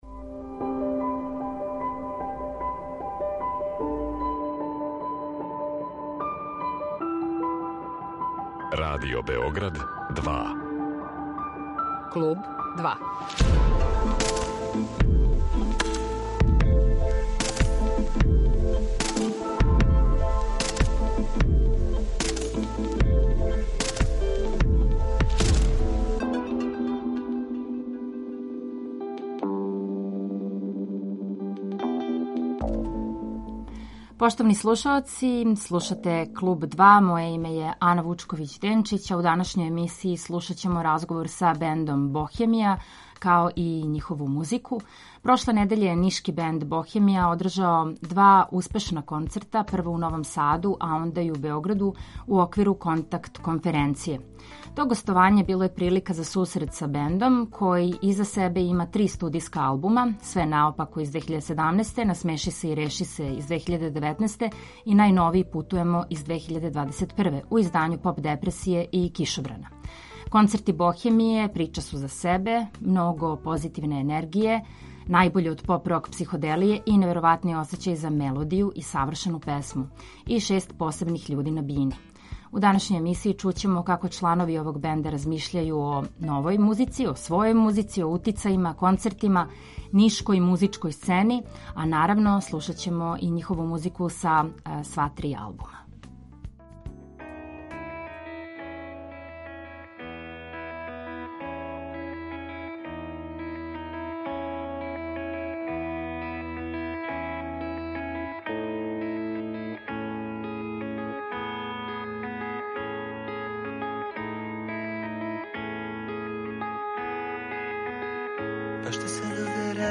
У данашњој емисији чућемо како чланови овог бенда размишљају о својој музици, утицајима, концертима, нишкој музичкој сцени, а наравно - слушаћемо њихову музику са сва три албума.